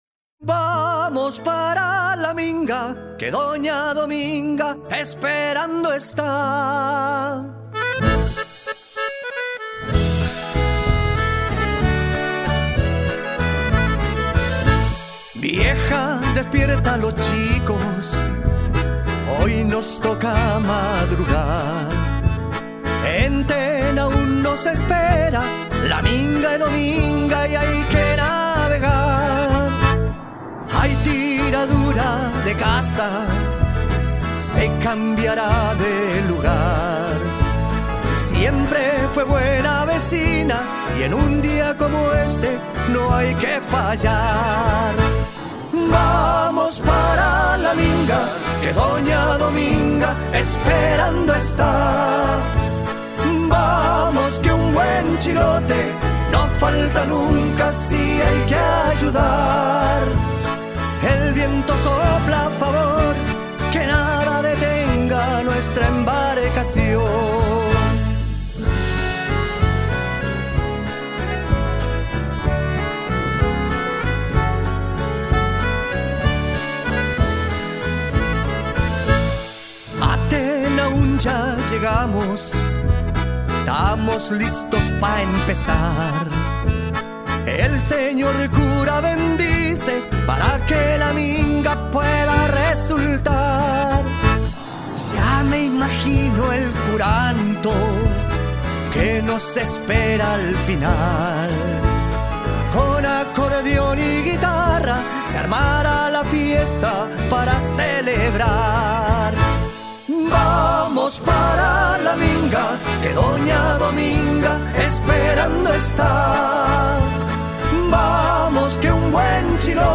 un vals chilote que relata la tradicional “tiradura de casa” en Tenaún